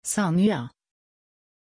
Aussprache von Sanya
pronunciation-sanya-sv.mp3